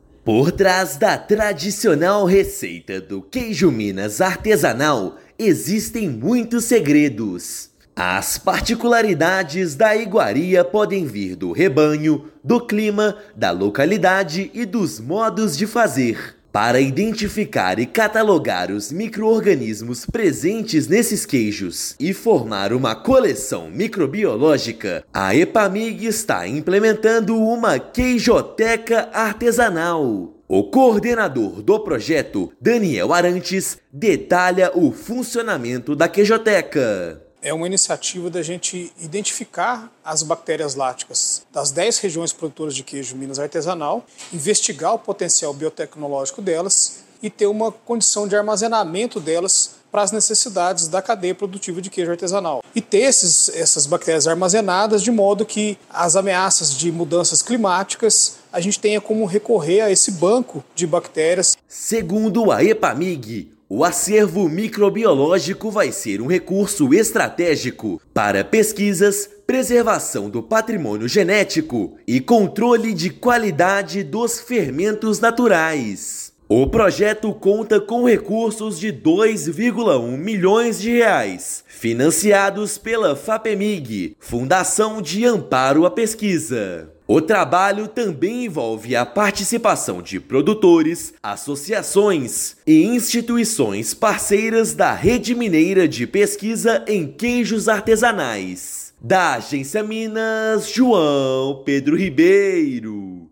Epamig conduz projeto para identificar e catalogar microrganismos e assegurar a biodiversidade dos produtos. Ouça matéria de rádio.